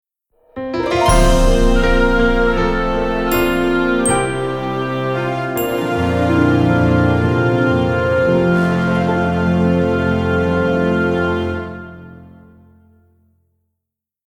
inspirational contemporary instrumental compositions